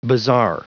Prononciation du mot bazaar en anglais (fichier audio)
Prononciation du mot : bazaar